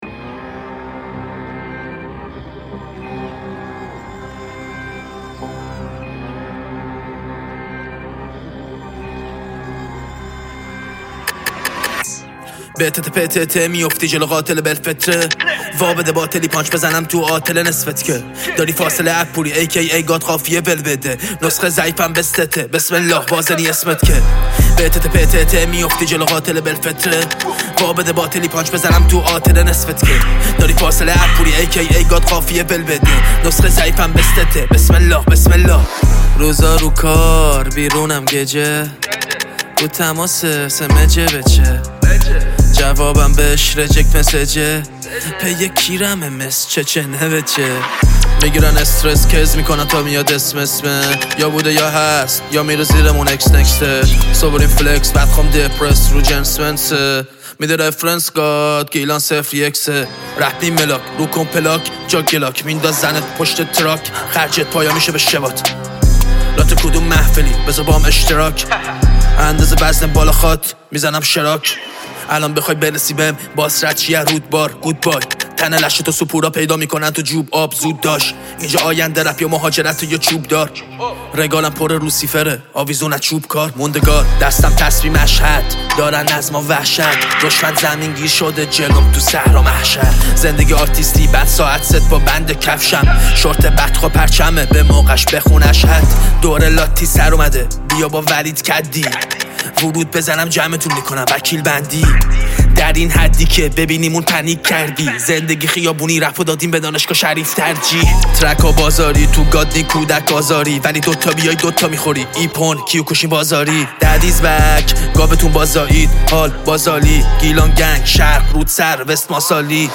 رپ
تک آهنگ